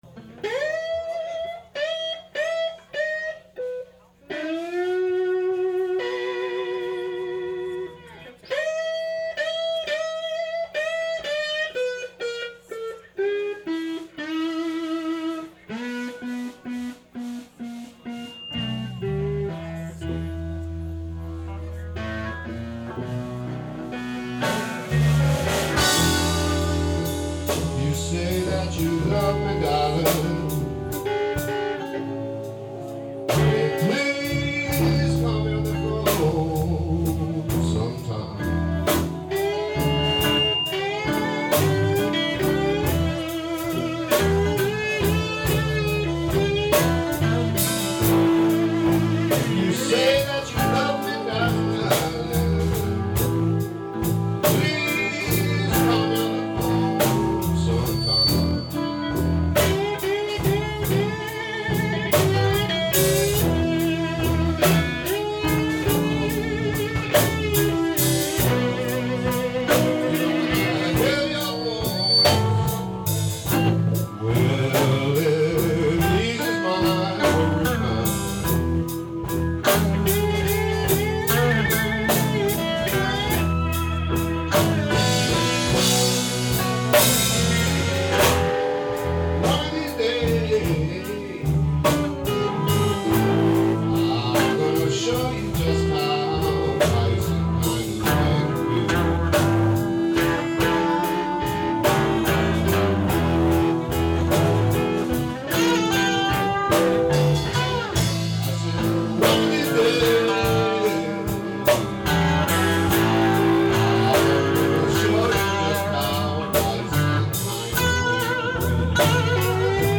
a slower blues jam
Please understand that not only were these outdoor audience recordings made under extremely difficult conditions, but also that much of the band had not even met before the party, let alone practiced together, and the jam lasted well under an hour.
original blues tune
slide work